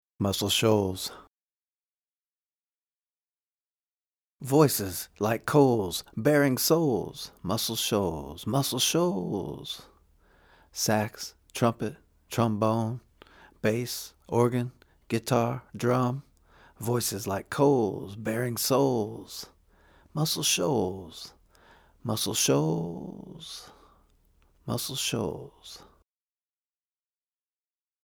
Original vocal:
muscle-shoals-1-3-vocal.mp3